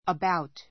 əbáut